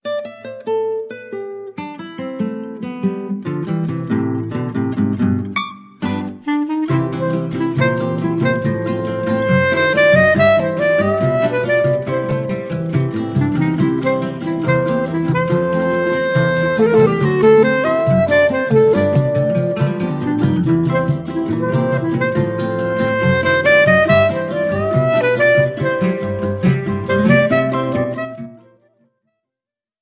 Choro style
clarinet
Level of difficulty easy